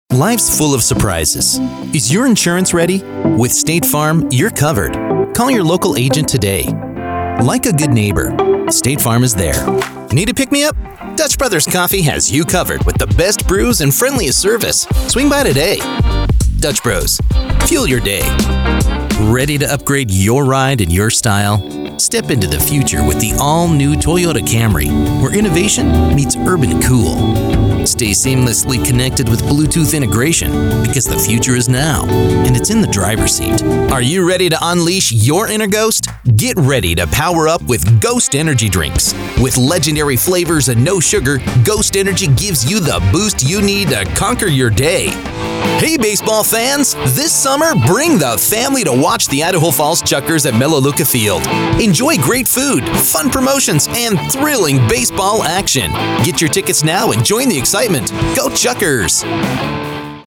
A professional voice actor with a warm, trustworthy, and versatile sound.
Commercial Demo
Commercialreel.mp3